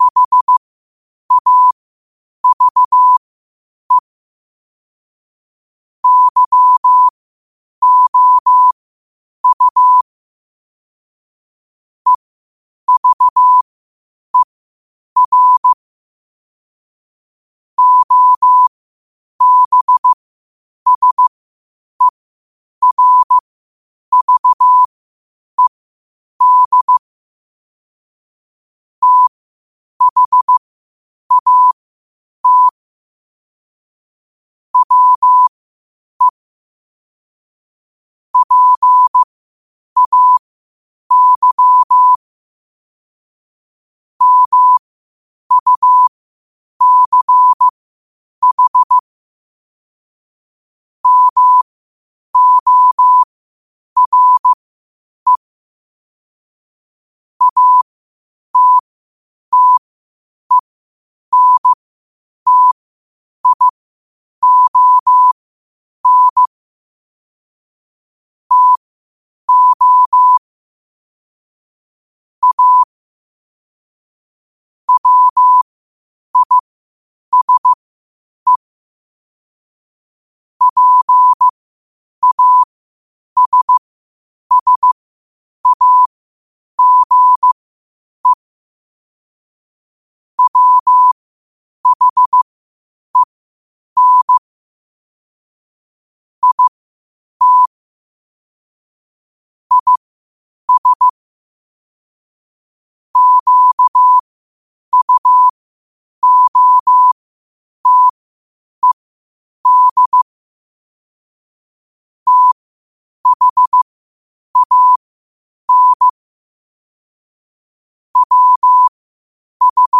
8 WPM morse code quotes for Wed, 13 Aug 2025 by QOTD at 8 WPM
Quotes for Wed, 13 Aug 2025 in Morse Code at 8 words per minute.